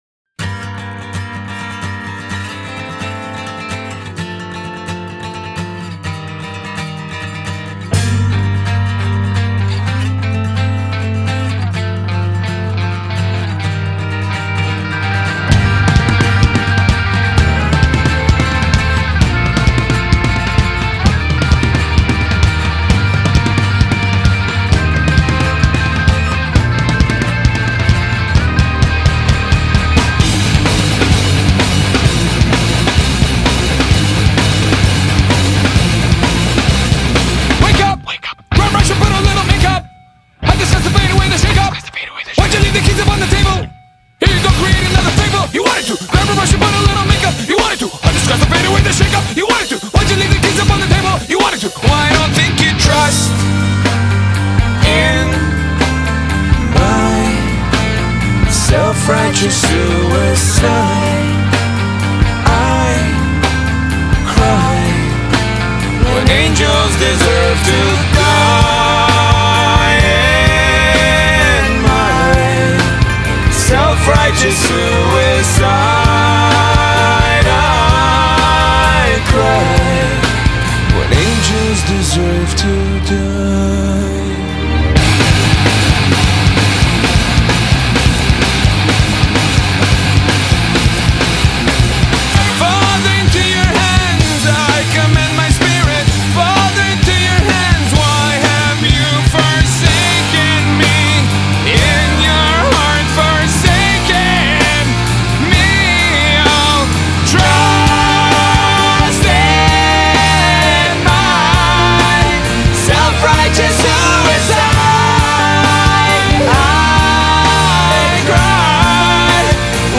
BPM62-262
Audio QualityPerfect (High Quality)
This song is nowhere near being constant.